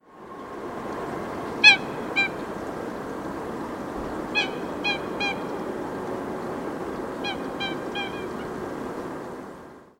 1 – wailing calls
These calls consist of short sequences of notes, some quite similar to day time calls. The exact shape of the notes can be very variable but the slightly halting, stuttered nature of the sequence is characteristic.
Coot typical call